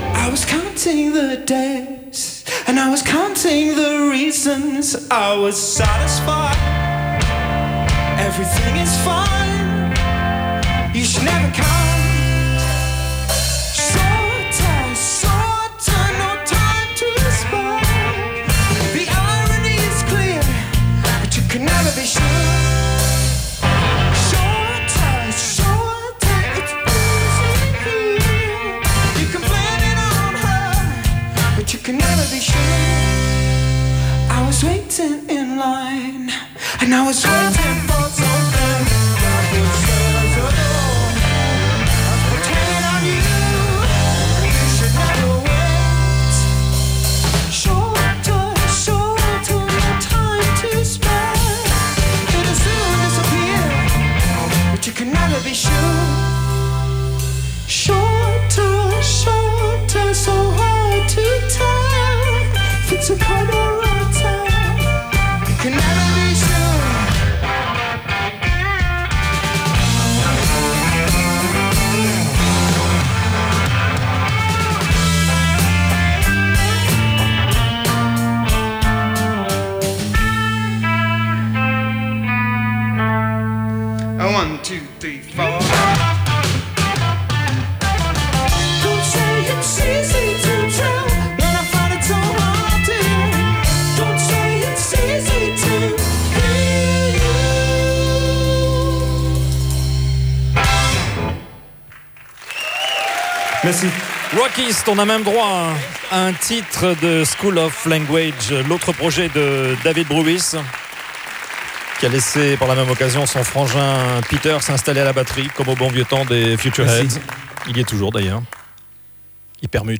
enregistrée le 22/02/2010  au Studio 105